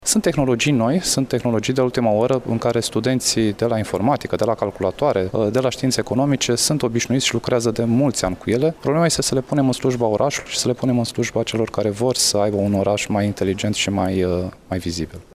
Evenimentul a avut loc cu prilejul desfăşurării Caravanei Smart City în localitate.